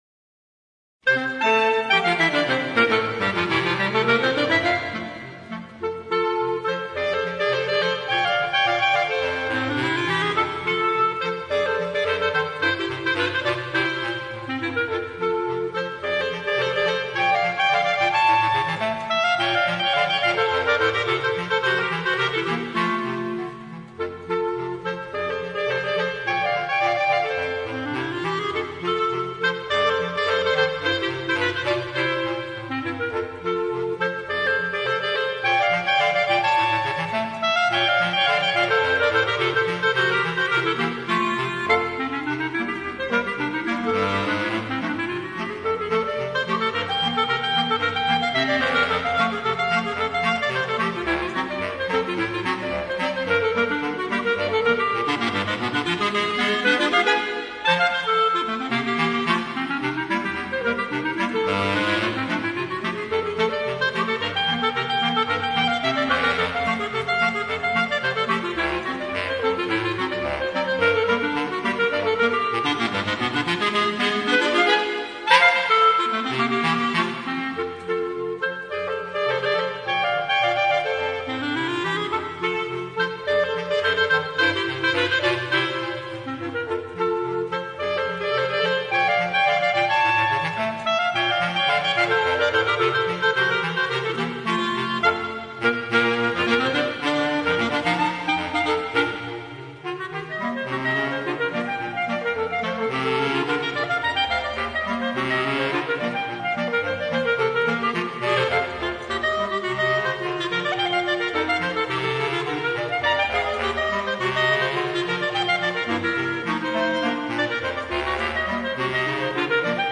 Per due clarinetti e clarinetto basso
trascritto per due clarinetti e clarinetto basso.